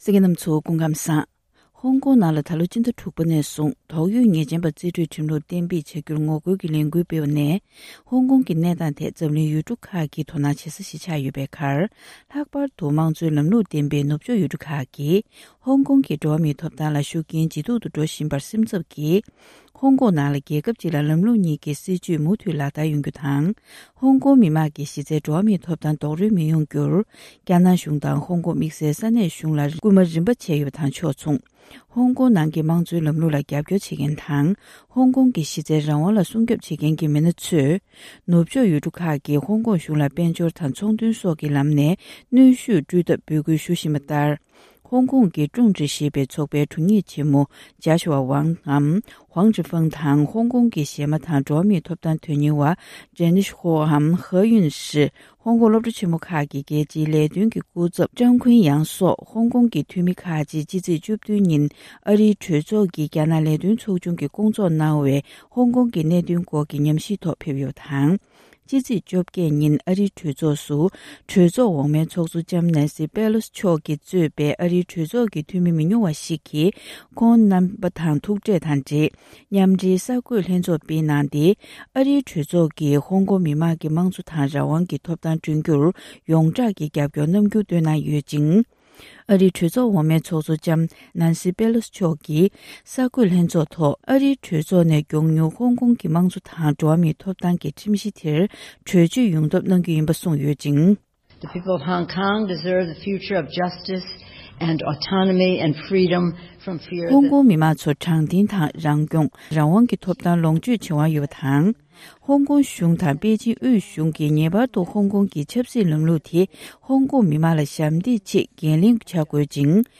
རྒྱ་ནག་དབུས་གཞུང་གིས་དབུ་འཁྲིད་ཚོས་ཧོ་ཀོང་གི་གནད་དོན་ཐག་གཅོད་ཀྱི་ལངས་ཕྱོགས་ཇི་འདྲ་ཞིག་འཛིན་ཀྱི་ཡོད་མེད་ཐད་གླེང་མོལ་ཞུས་པ།